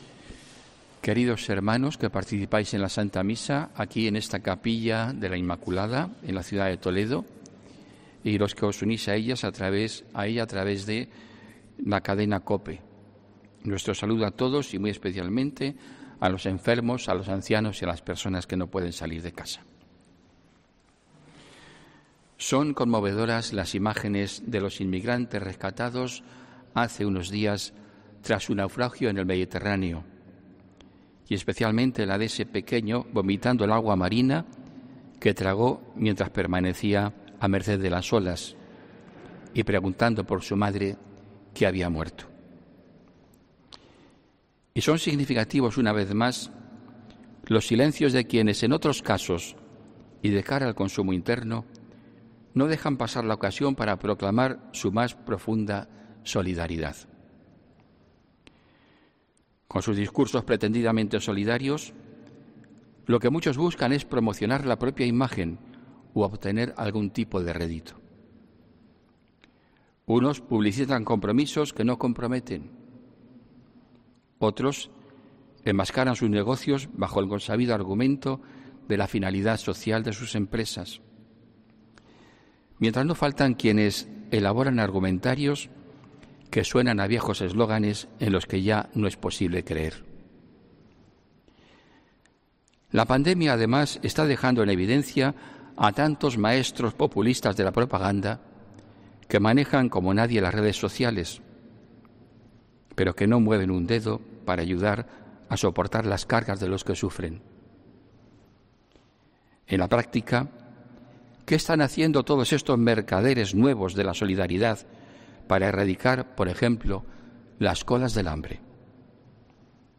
HOMILÍA 15 DE NOVIEMBRE DE 2020